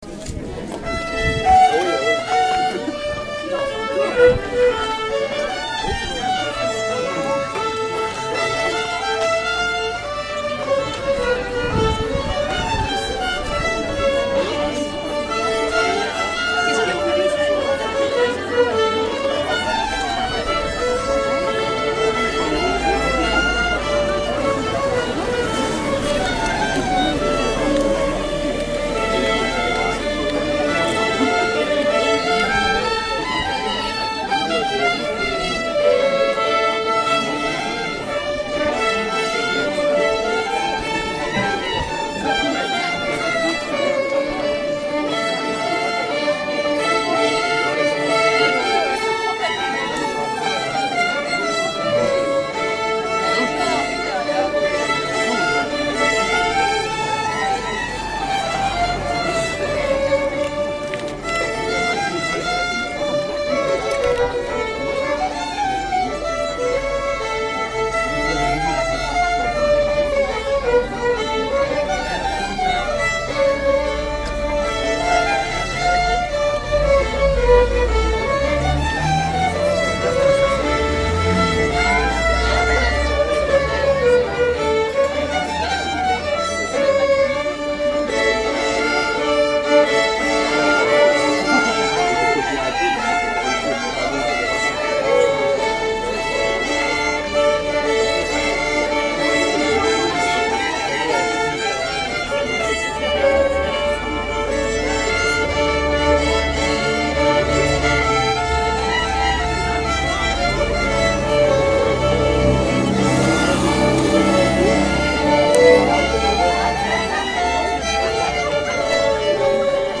02_-violons.mp3